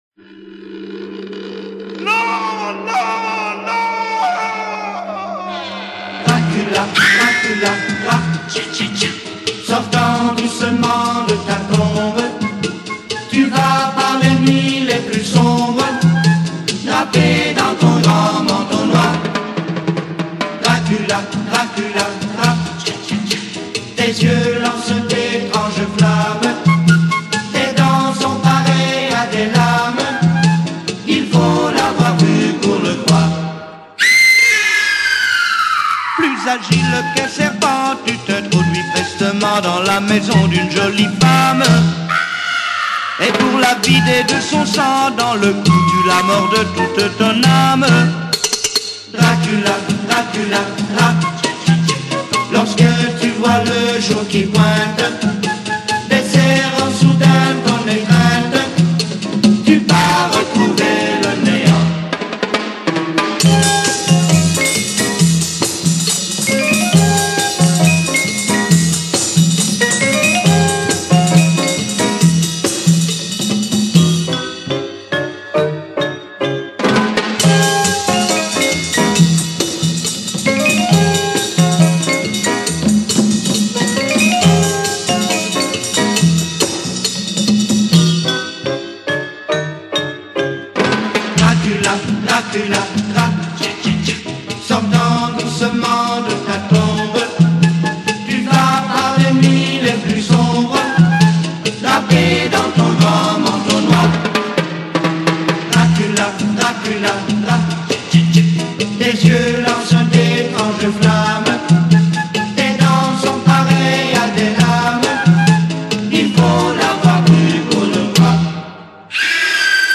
un cha cha cha au sujet du vampire retient l’attention